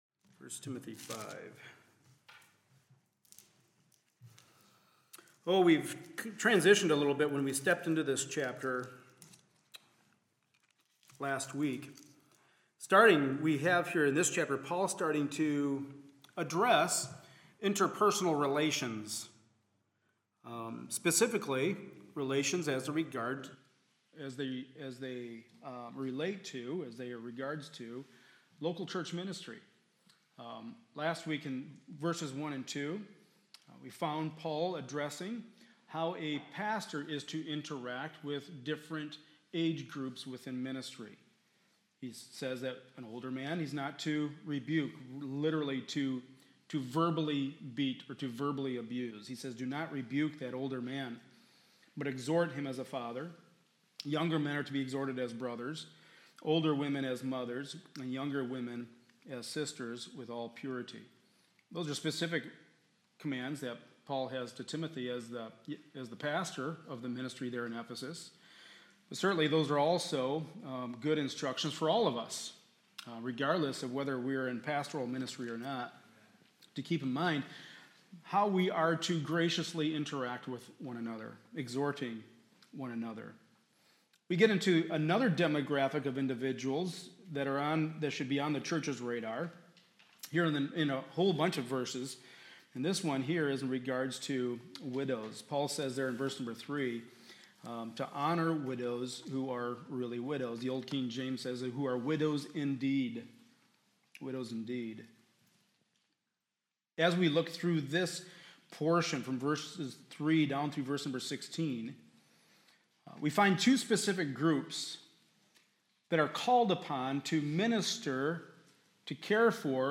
Passage: 1 Timothy 5:3-16 Service Type: Sunday Morning Service